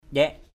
/ʄɛʔ/ (đg.) uốn = se tortiller. tamia njaik rup tm`% =WK r~P múa mà uốn mình = danser en tortillant du corps.